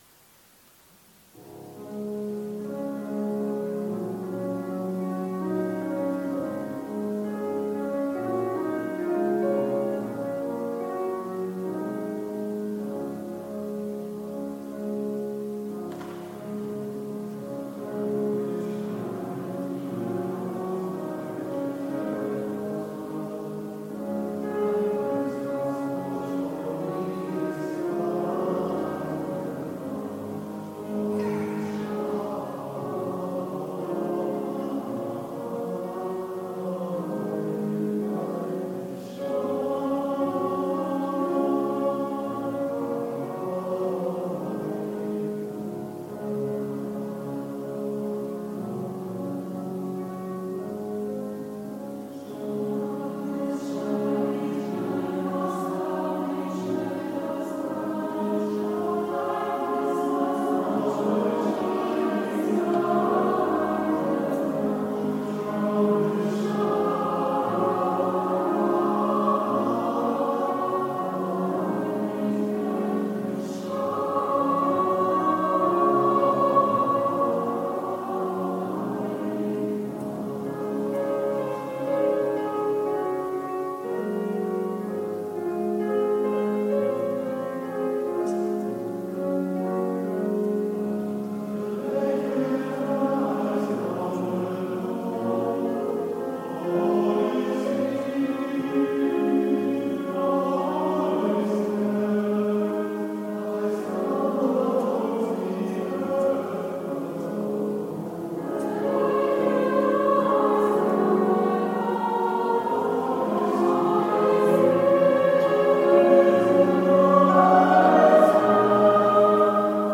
Diese sind Mitschnitte aus verschiedenen Konzerten.
Sure on this shining night (Christuskirche Schöneweide 18. 12. 21